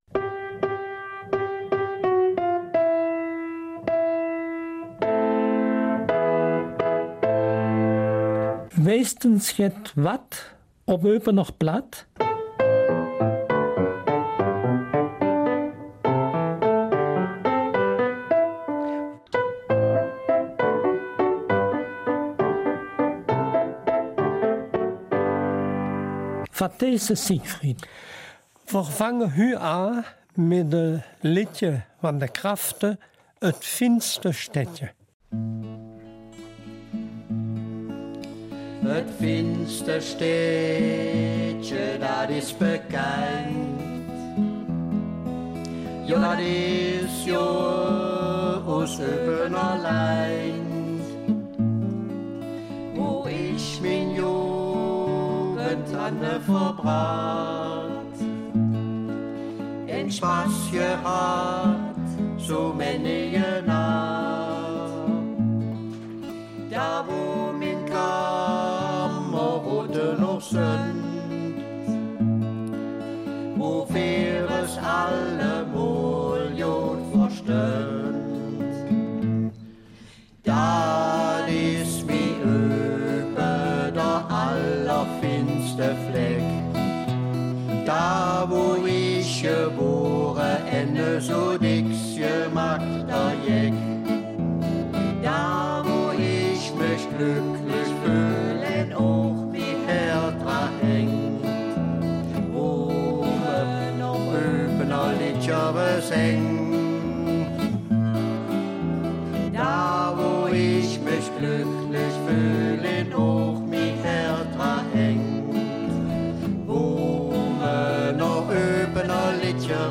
Eupener Mundart - 9. April